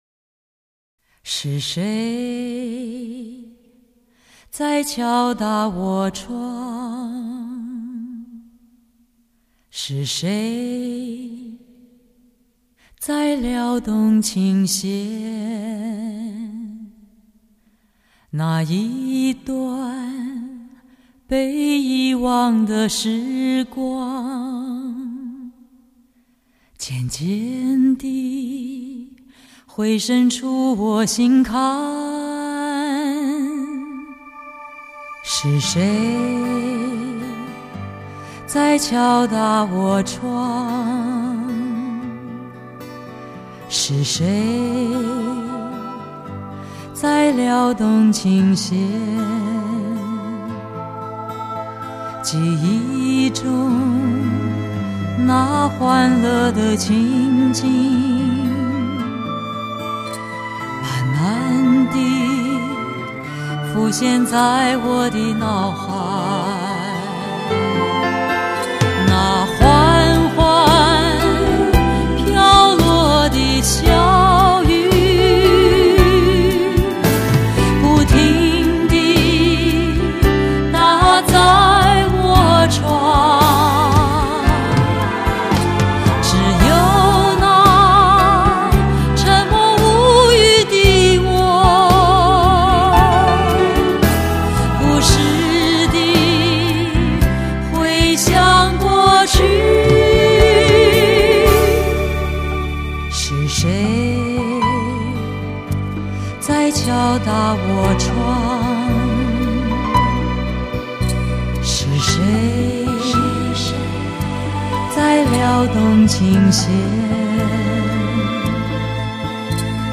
CDA 中文人声试音